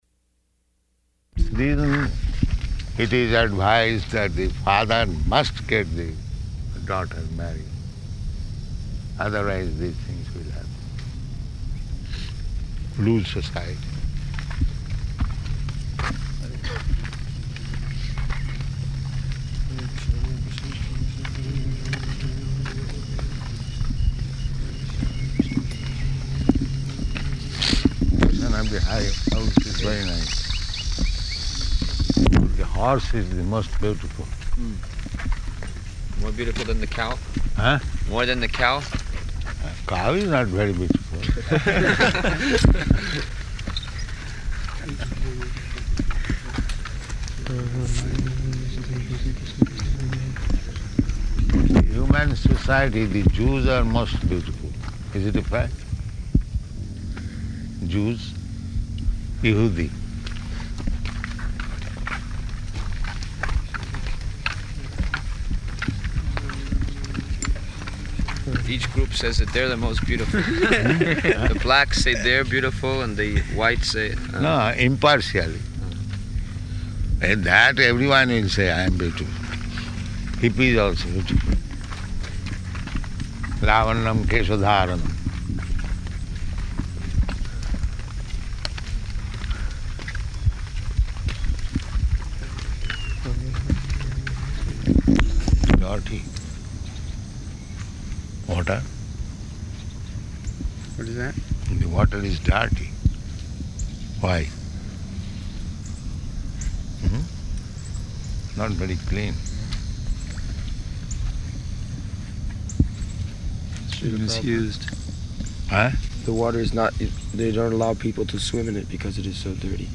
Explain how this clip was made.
Morning Walk Type: Walk Location: Dallas